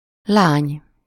Ääntäminen
IPA: [fi.jɛt]